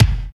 80 KICK.wav